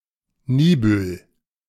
Niebüll (German: [ˈniːbʏl]
De-Niebüll.ogg.mp3